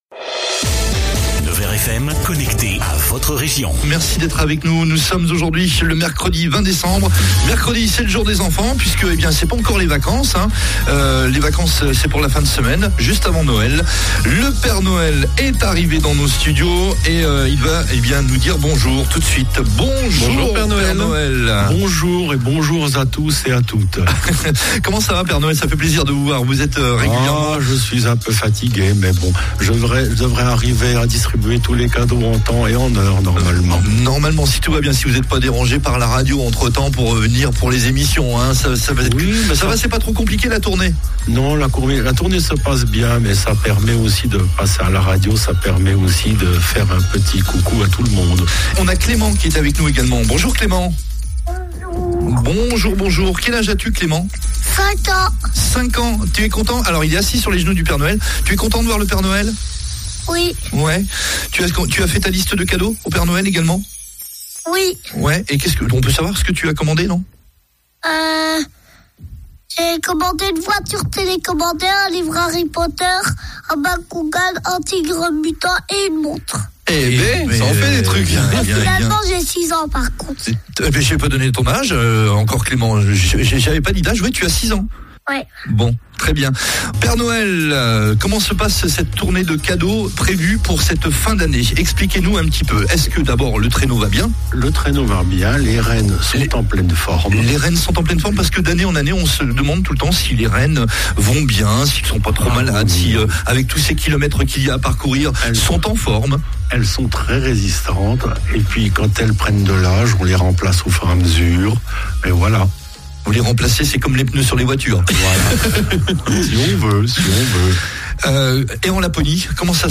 Le Père Noël en direct des studios